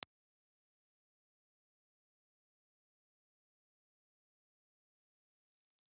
Condenser
Cardioid
Impulse Response file of the Sony ECM-56A in 'V' position
Sony_ECM56A_V_IR.wav